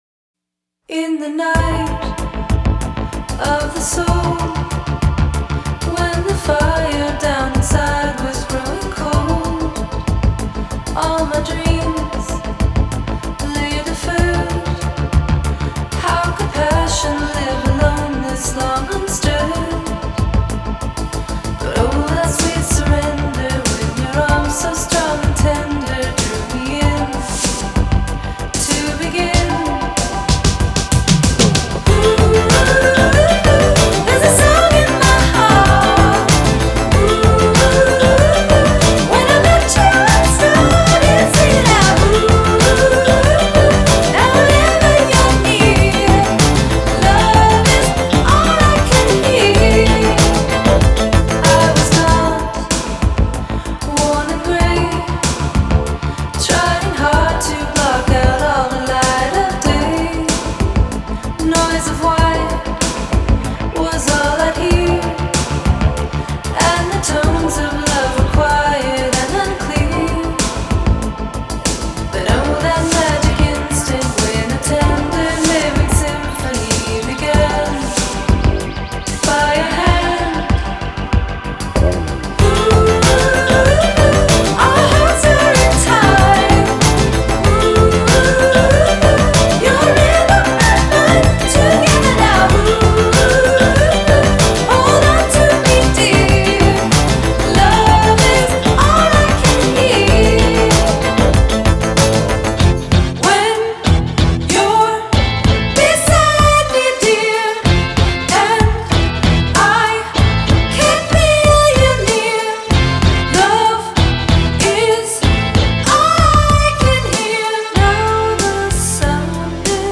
Genre: Indie Pop, Synthpop, Female Vocal